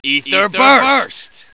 His English voice actor is just as awesome because of that line.
Ether Burst.wav